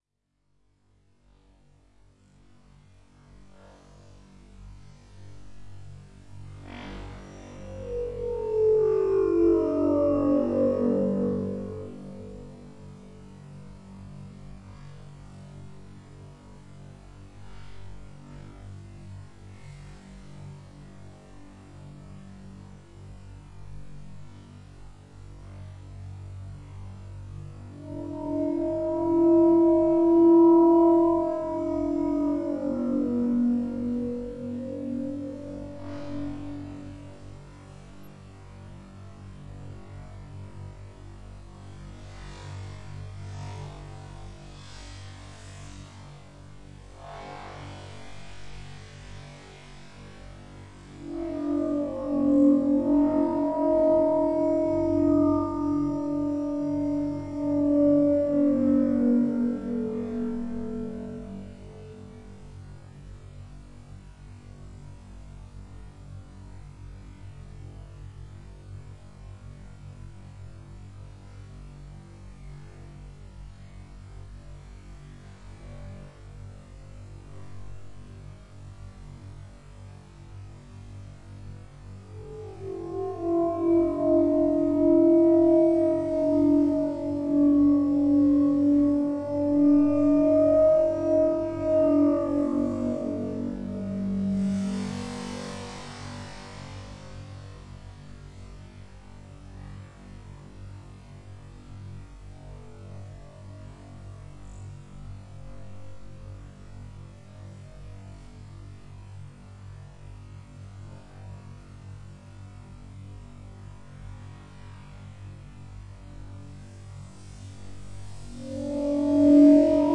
加工过的马拉穆特犬嚎叫声 " 鲍里斯拉长的呻吟声
描述：这是我三岁的雄性马拉姆特犬发出的一系列呻吟。
在这个时间拉伸的版本中，谐波泛音得到了强调。原始的呻吟声是在室内用铁三角BP4025立体声话筒直接录入马兰士PMD661录音机的，分辨率为24比特，采样率为48kHz.
标签： 声景 嘶哑 处理 抽象的 雪橇 现场记录
声道立体声